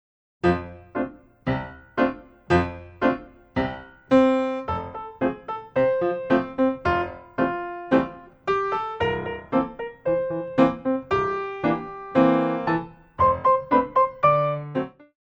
Grand Battement